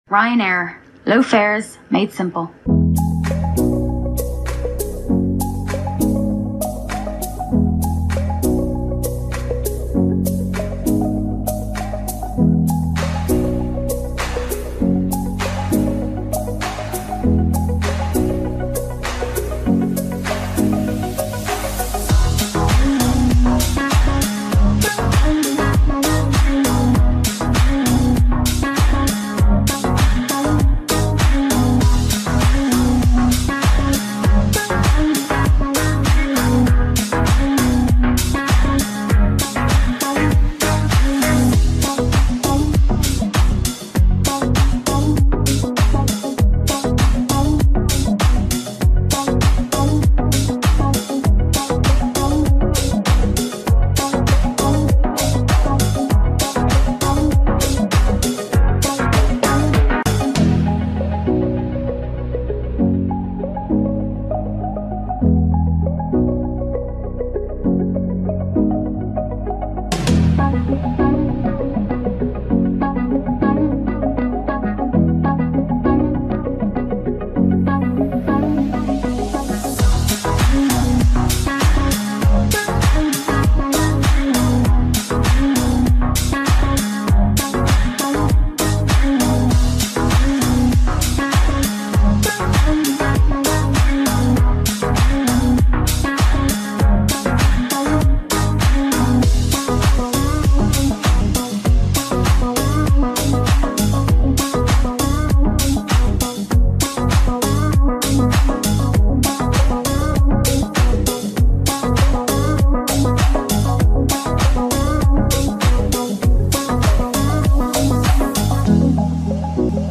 BoardingMusic[1][Morning].ogg